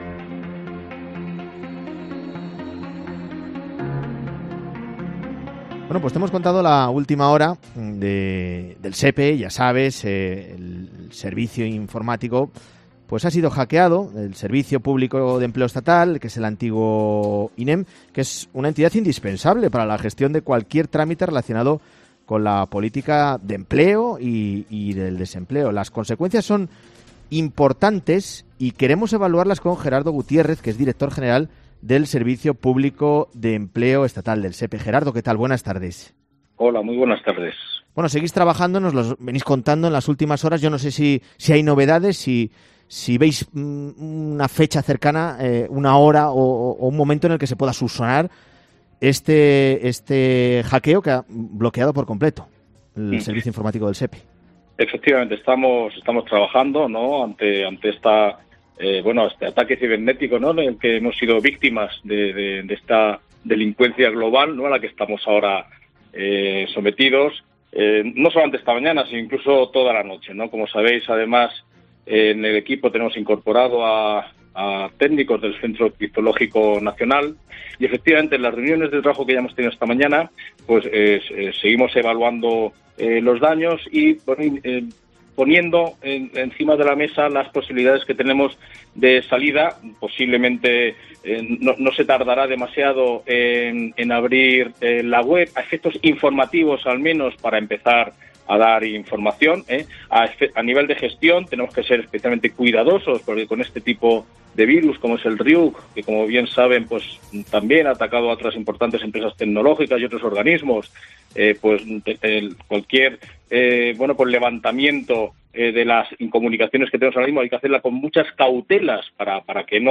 Hablamos con Gerardo Gutiérrez, Director General del Servicio Público de Empleo Estatal, SEPE.